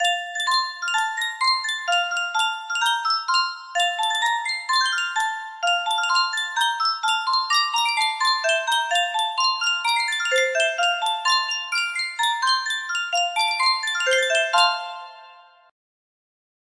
Yunsheng Music Box - Pick a Bale of Cotton 6185 music box melody
Full range 60